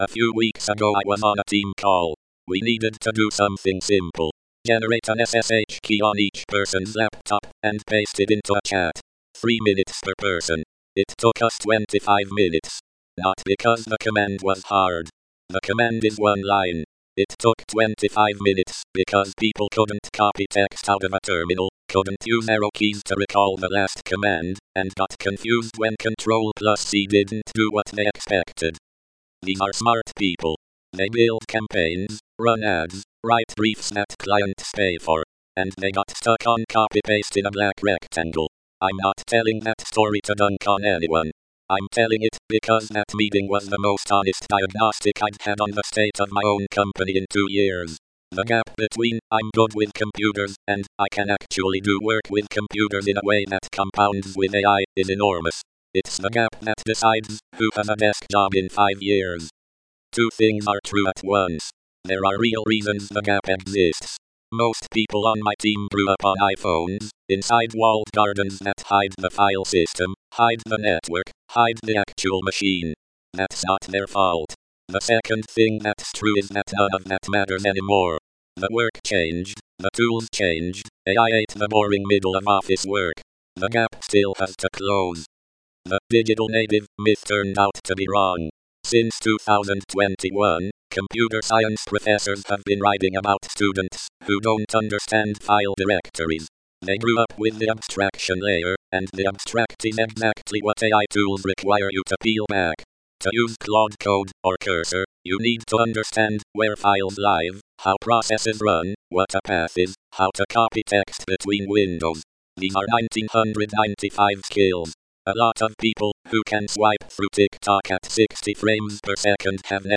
Audio summary.
A six-minute spoken version of this post is below.